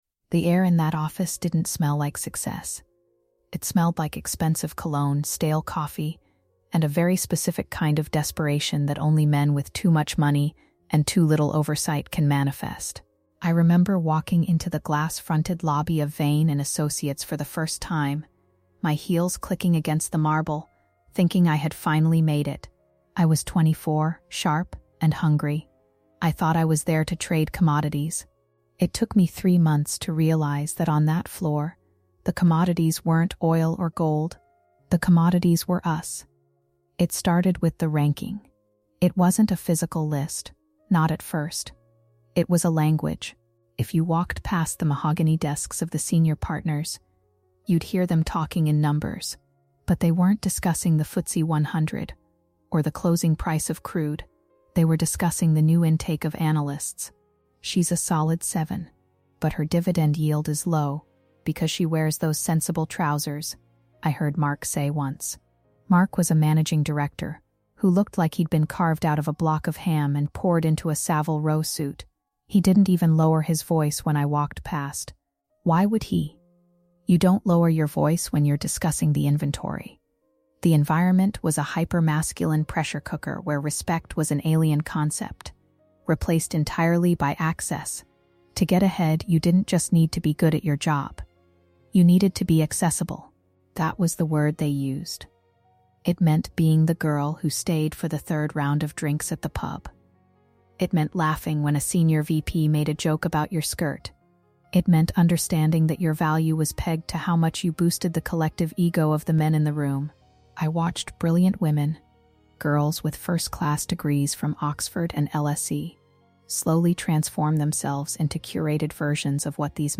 Listen as our narrator navigates the betrayal of peers and the predatory expectations of leadership, ultimately facing the crushing realization that she has become a participant in her own devaluation.